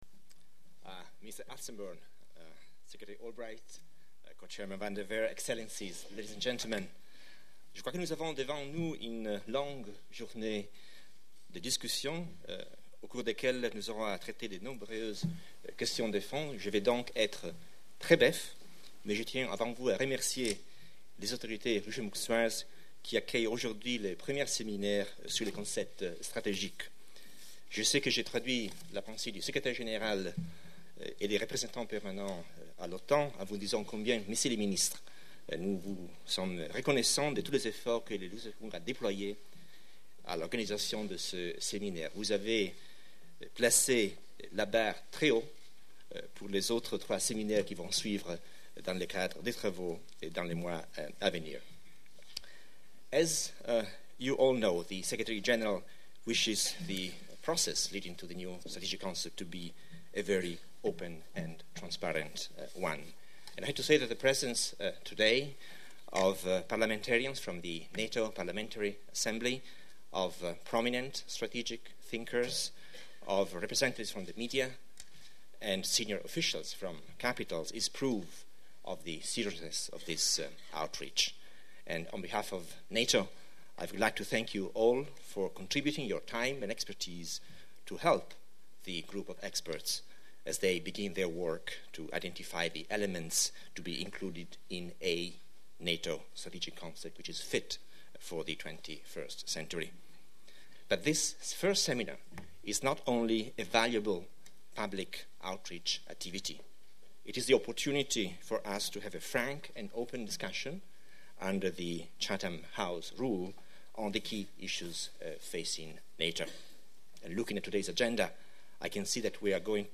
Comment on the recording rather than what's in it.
NATO’s new Strategic Concept discussed at Luxembourg seminar